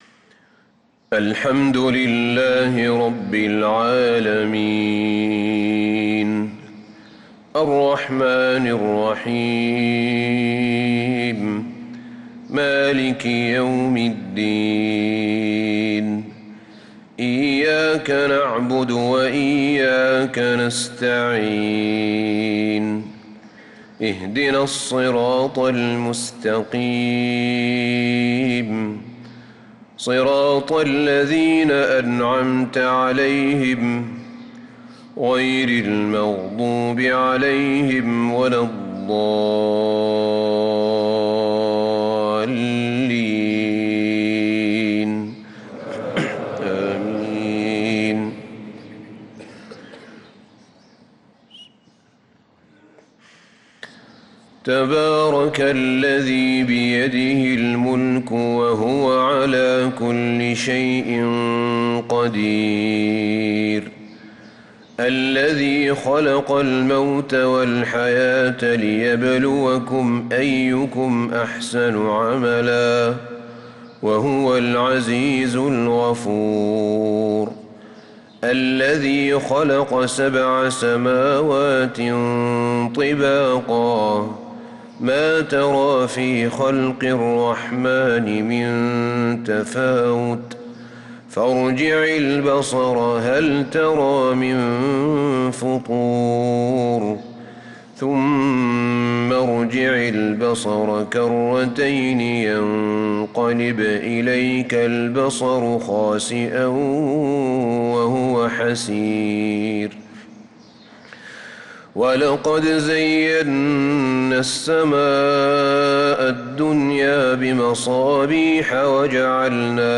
فجر الأحد 3-8-1446هـ سورة الملك كاملة | 2-2-2025 Fajr prayer Surat al-Mulk > 1446 🕌 > الفروض - تلاوات الحرمين